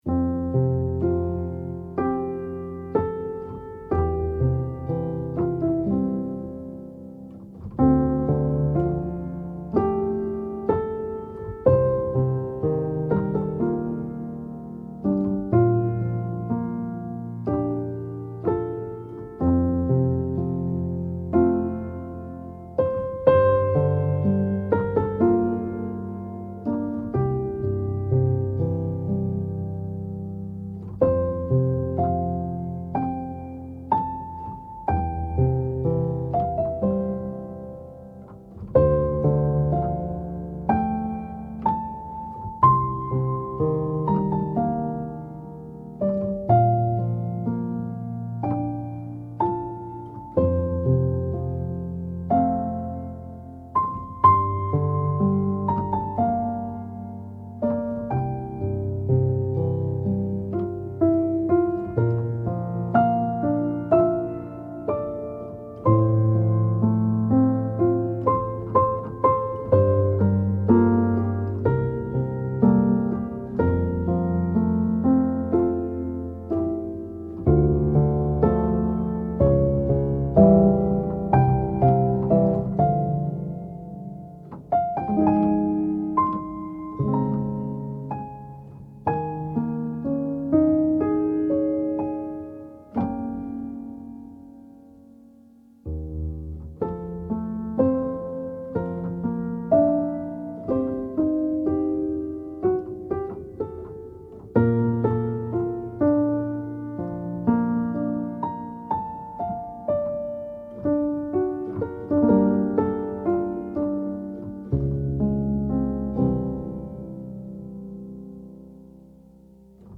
感動的なシーンで流したいピアノBGM
アコースティック, シネマチック 4:09